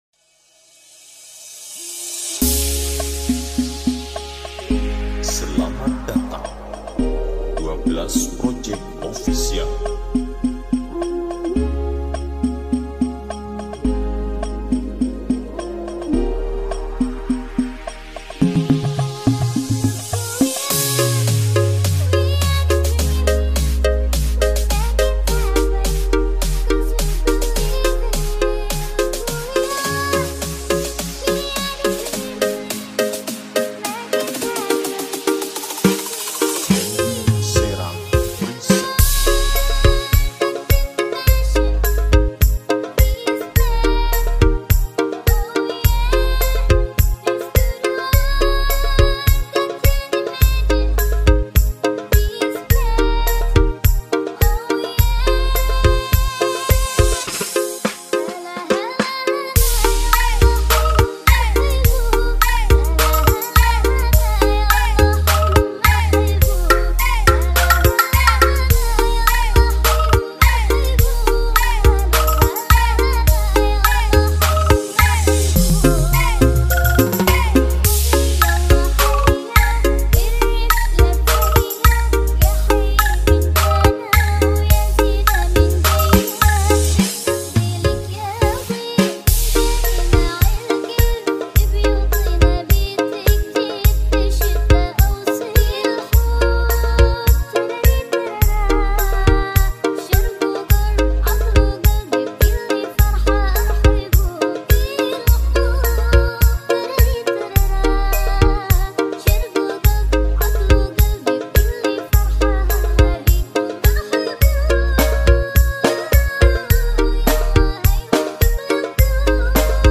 cek sound bass